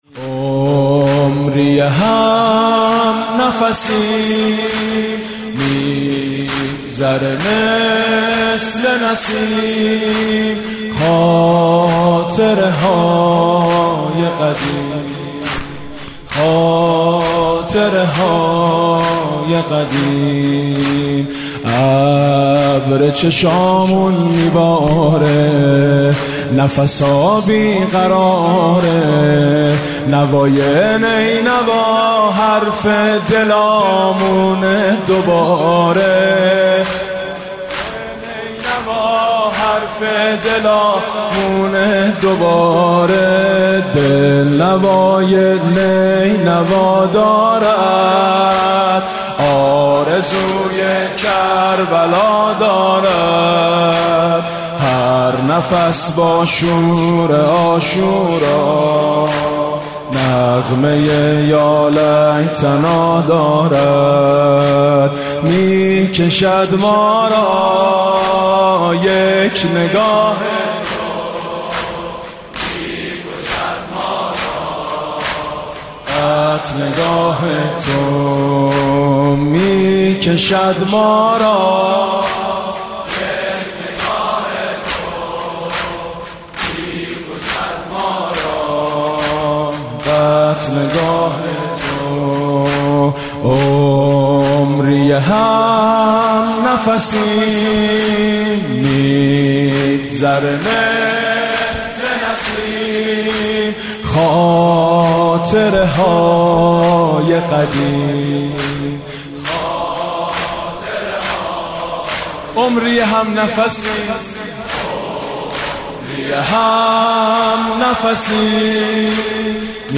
مراسم شب تاسوعای محرم الحرام سال 1395 با نوای میثم مطیعی.